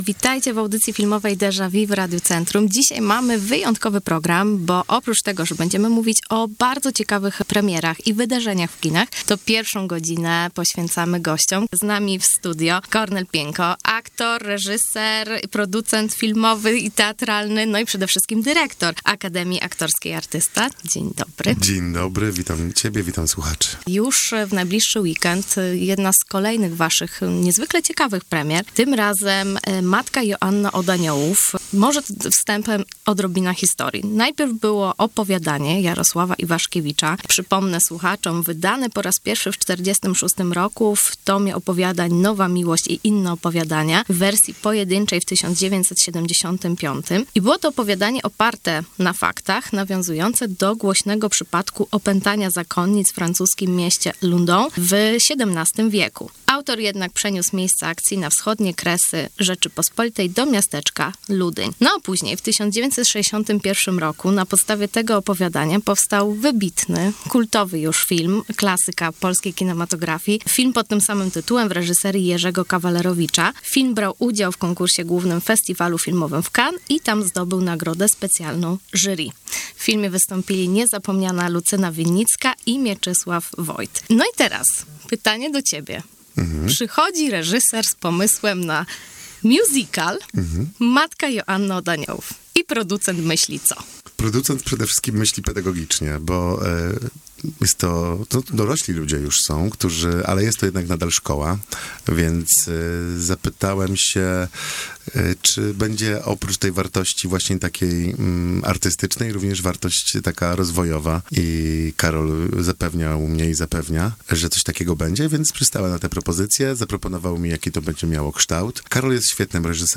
Matka Joanna od Aniołów – nowy spektakl w Akademii Aktorskiej Artysta – rozmowa